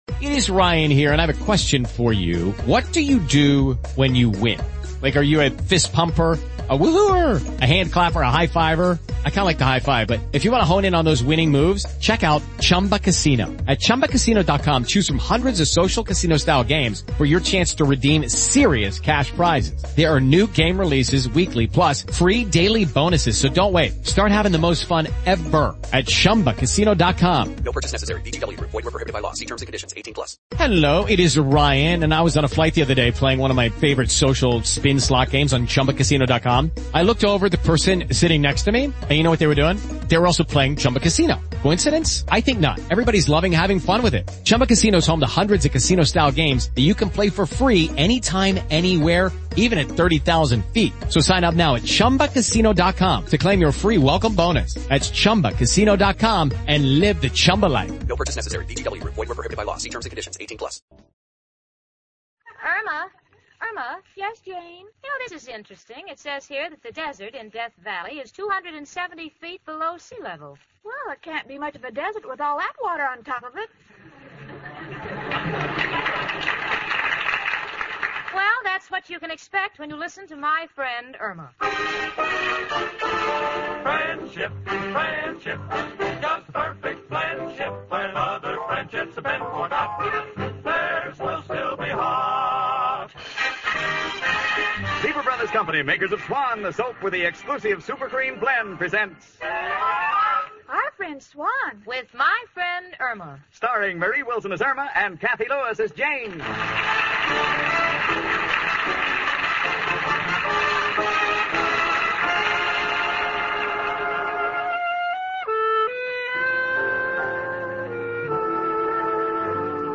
"My Friend Irma," the classic radio sitcom that had audiences cackling from 1946 to 1952!
Irma, played to perfection by the inimitable Marie Wilson, was the quintessential "dumb blonde."